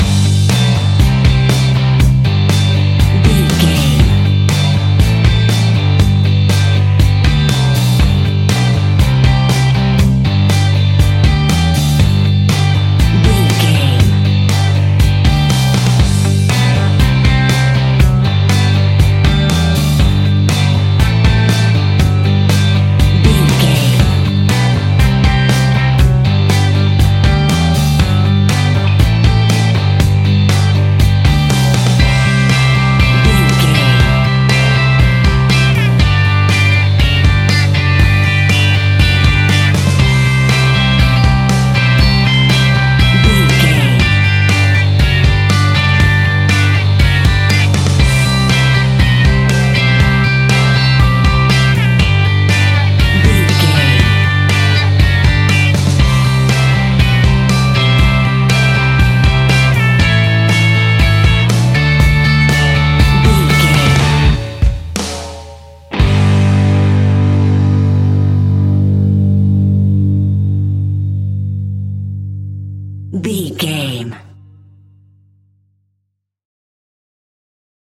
Ionian/Major
D
energetic
uplifting
instrumentals
upbeat
groovy
guitars
bass
drums
piano
organ